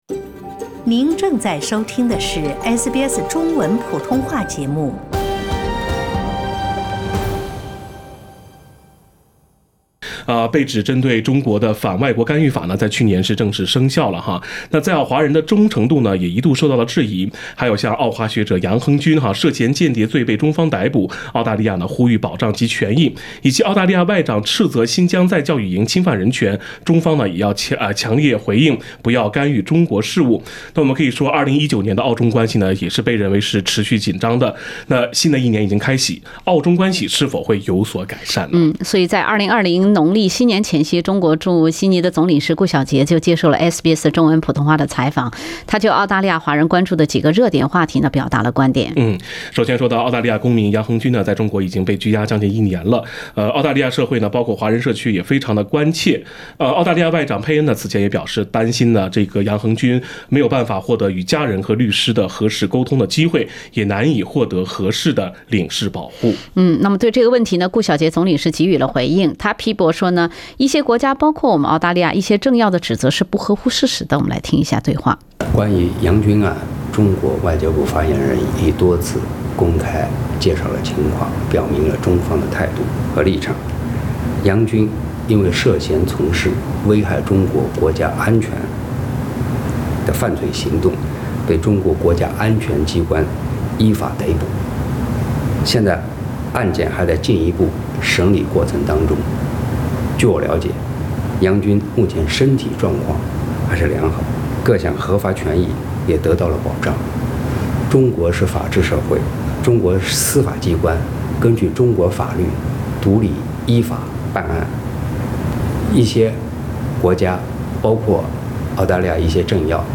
澳中关系何去何从？中国驻悉尼总领事顾小杰专访